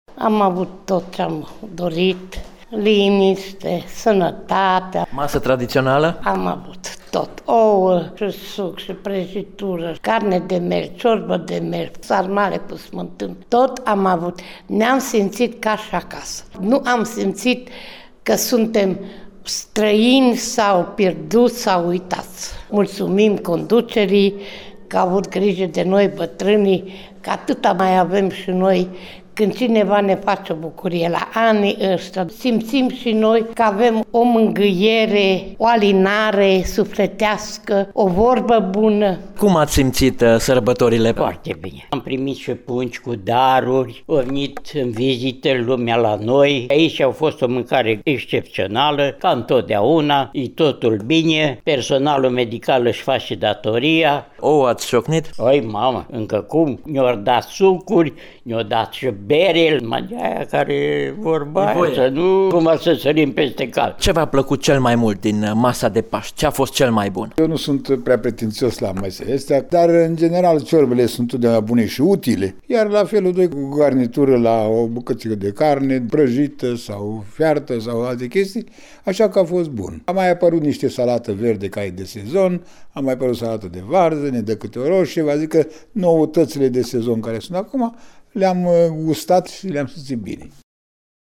a stat de vorbă cu  câţiva din vârstnici: